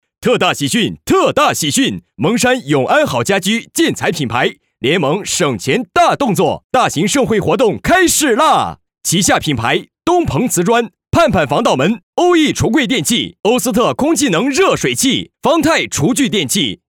男79实惠大气-纵声配音网
男79 【干声】 蒙山永安好家居.mp3